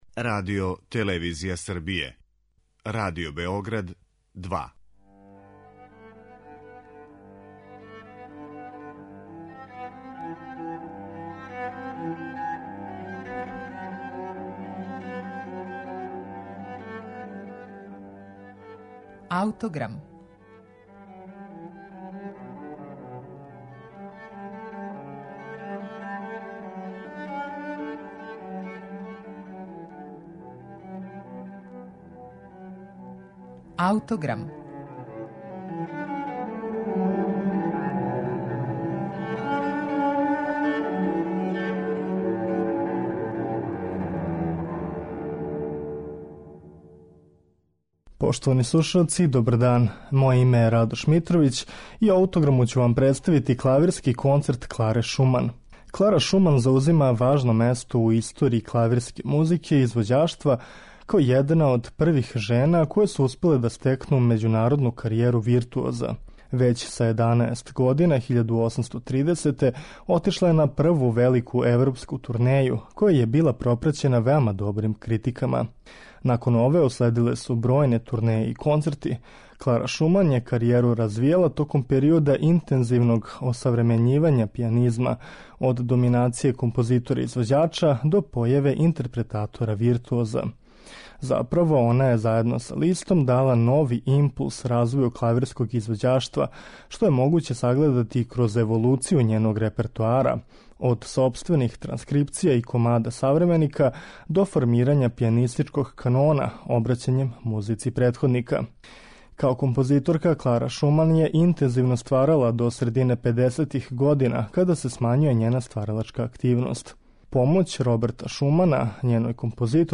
Први клавирски концерт Кларе Шуман